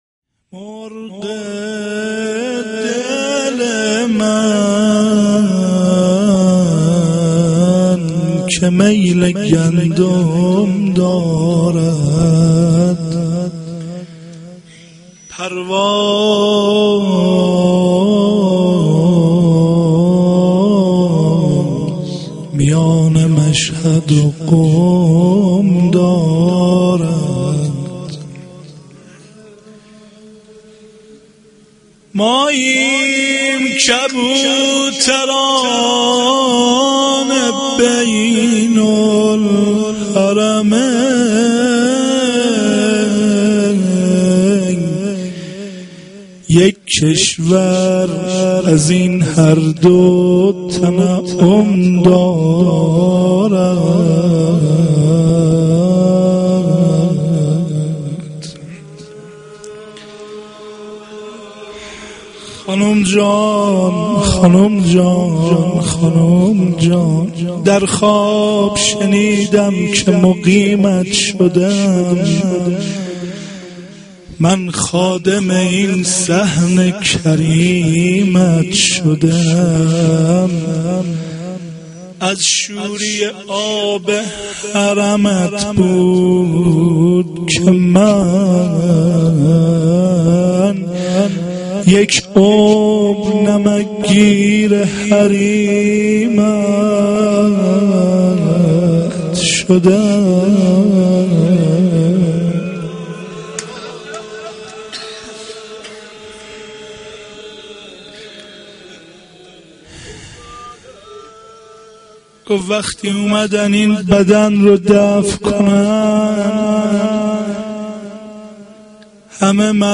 shahadate-h.-masoumeh-s-92-rozeh-akhar.mp3